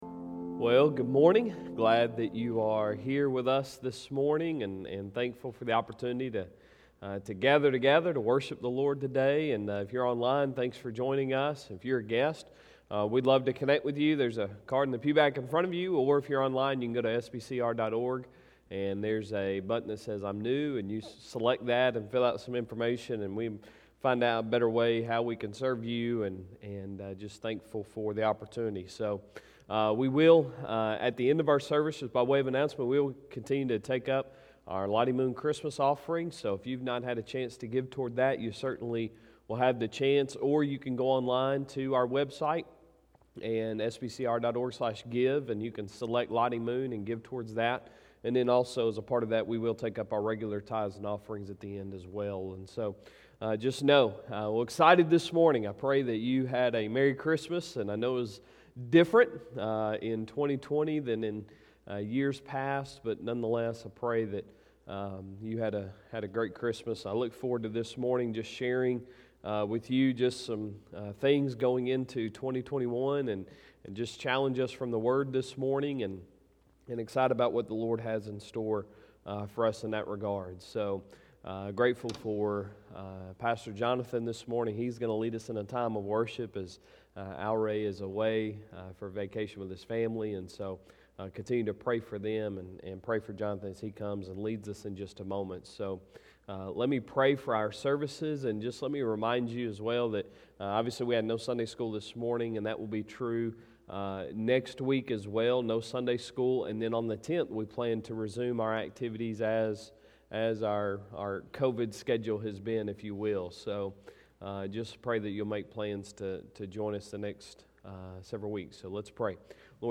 Sunday Sermon December 27, 2020